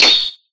Sword1.ogg